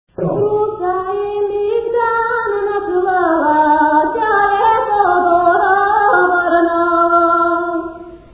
музикална класификация Песен
форма Двуредична
размер Две четвърти
фактура Едногласна
начин на изпълнение Солово изпълнение на песен
битова функция На хоро
фолклорна област Родопи (Южна България)
място на записа Гледка
начин на записване Магнетофонна лента